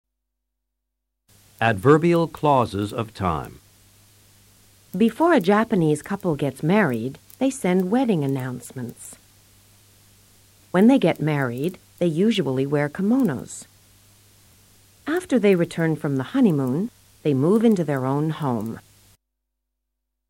Escucha a la profesora y presta atención al uso de las CLAUSULAS ADVERBIALES DE TIEMPO.